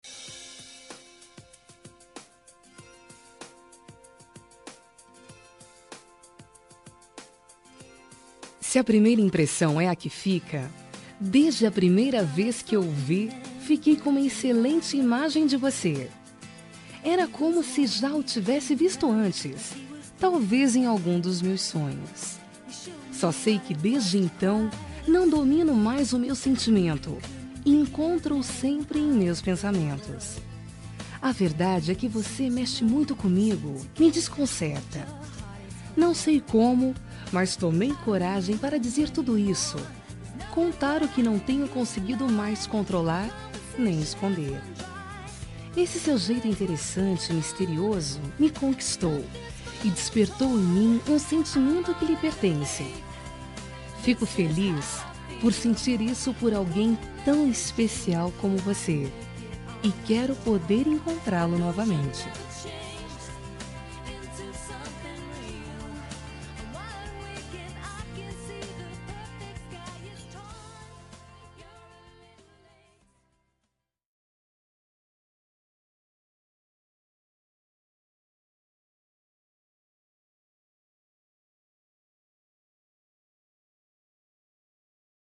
Telemensagem Paquera – Voz Feminina – Cód: 051585